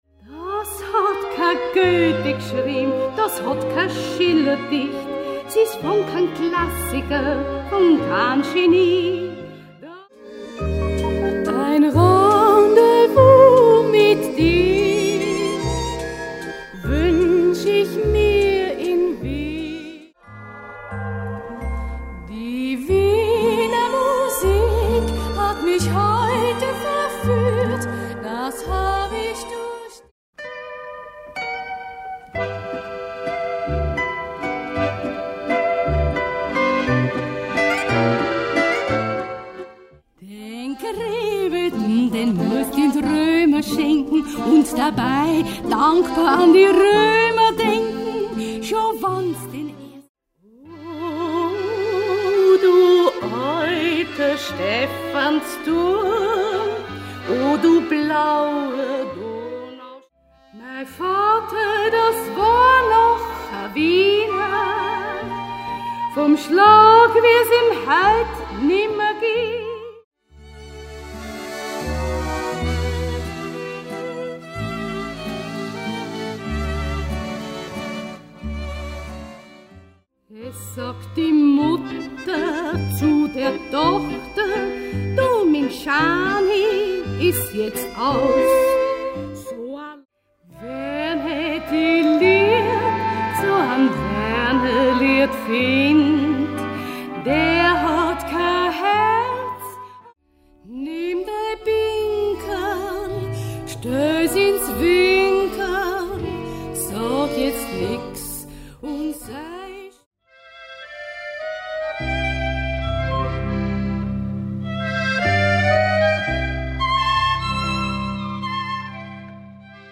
Wienerlied, instrumental
Hier ein kurzer Schnelldurchlauf durch alle Titeln.